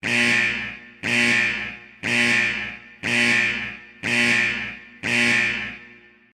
Free SFX sound effect: Military Base Nuclear Alarm.
Military Base Nuclear Alarm
yt_qjxMe6vRkGE_military_base_nuclear_alarm.mp3